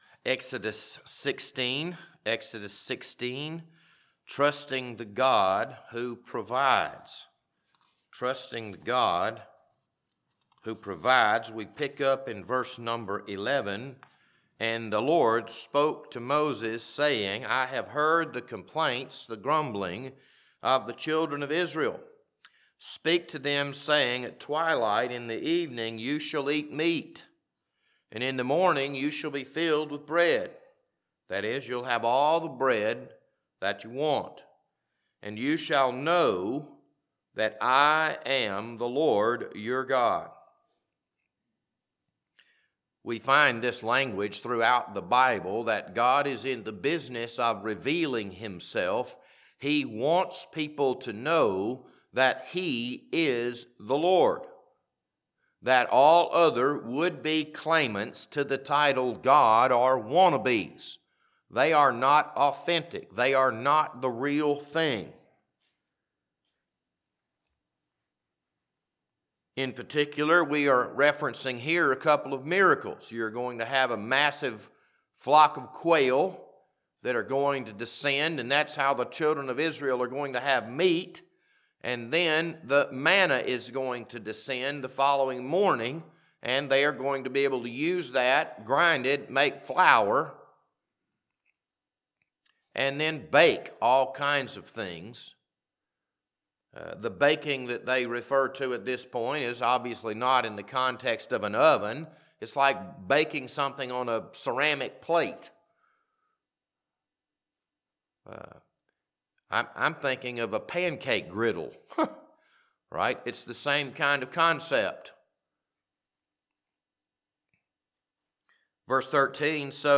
This Wednesday evening Bible study was recorded on February 25th, 2026.